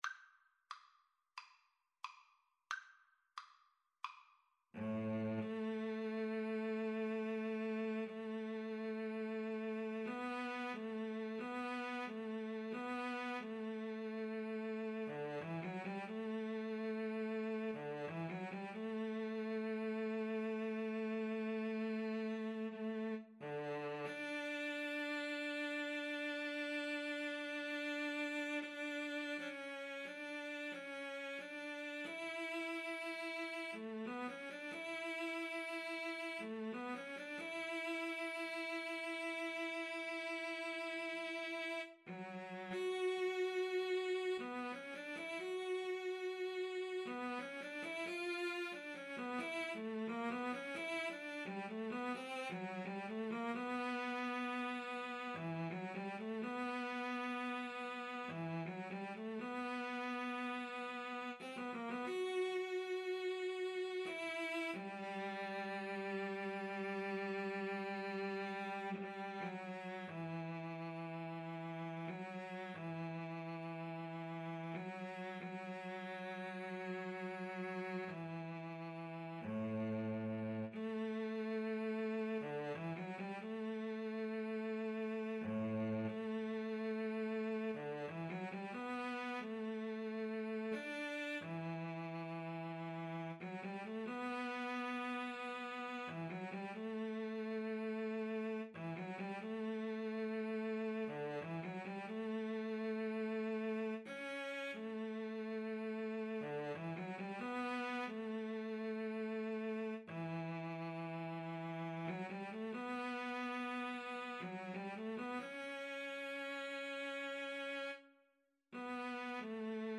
Ruhig bewegt = c. 90
Classical (View more Classical Cello Duet Music)